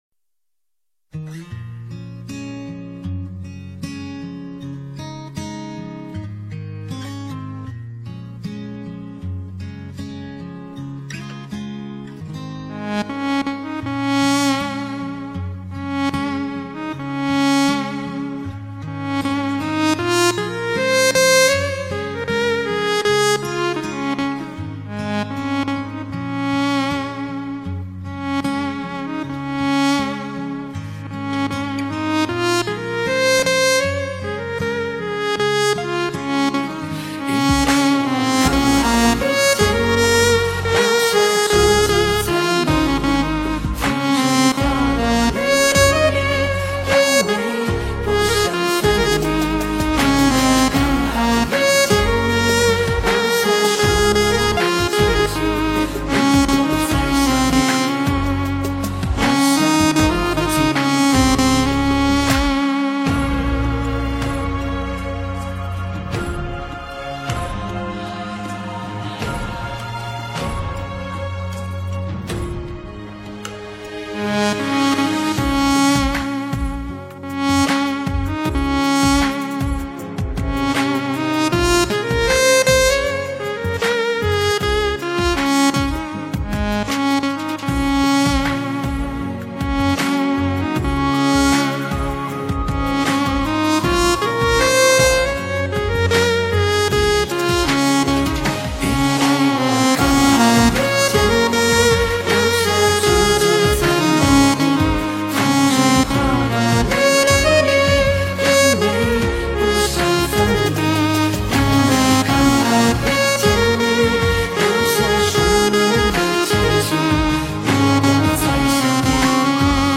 giai điệu hiện đại và sôi động.
bản nhạc không lời chất lượng cao
Sáo Điện Tử (EWI)